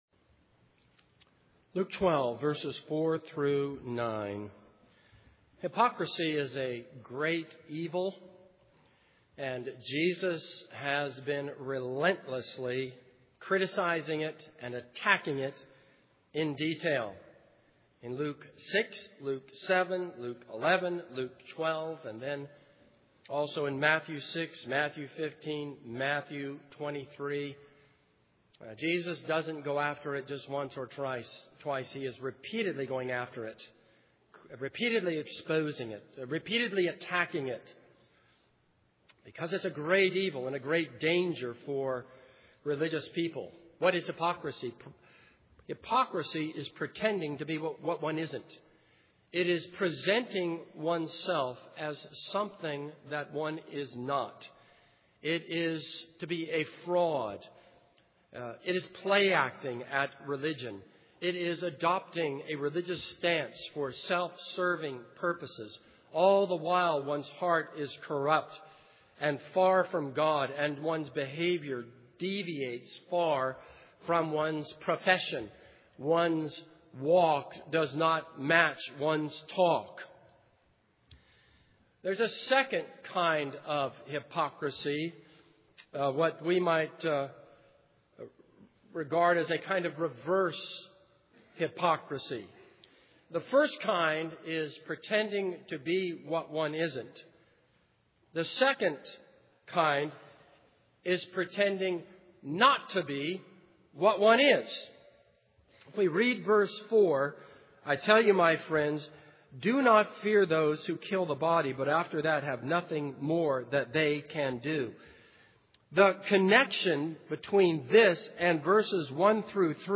This is a sermon on Luke 12:4-9.